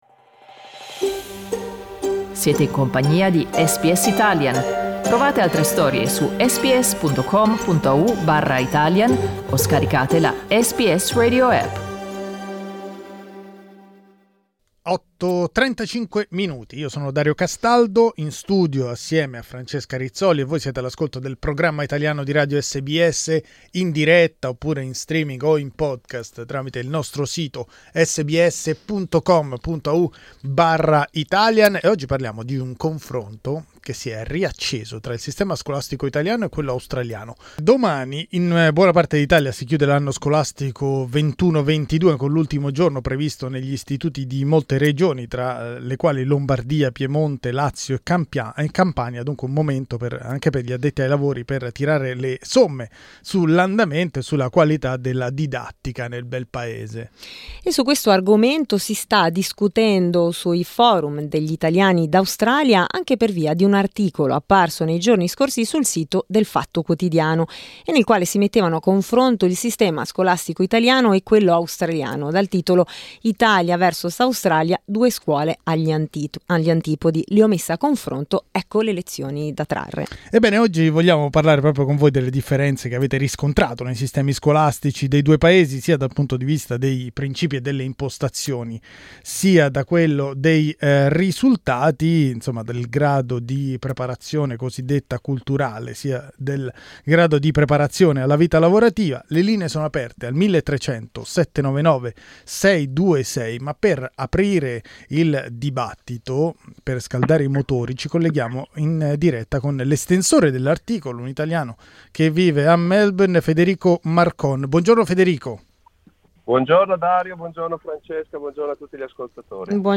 I genitori italiani d'Australia raccontano le loro esperienze, mettendo in luce le differenze tra due mondi davvero agli antipodi.